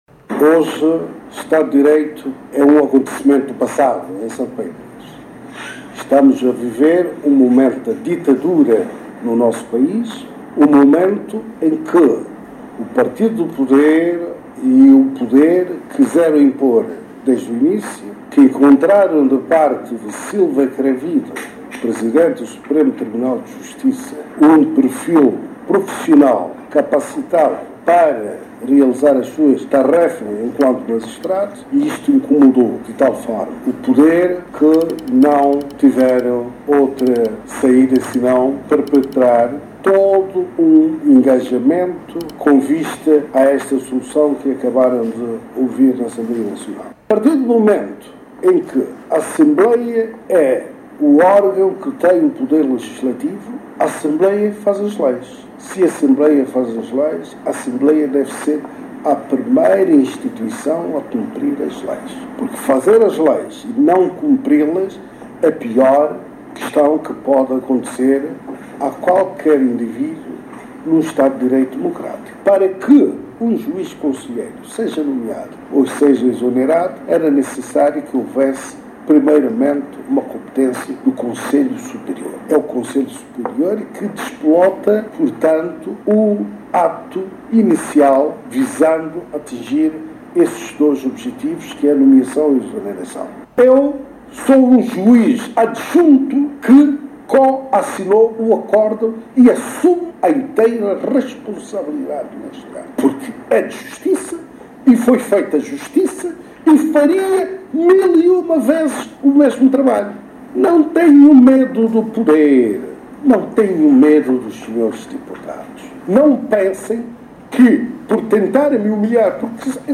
Declaração do Juiz Presidente do Supremo, Silva Cravid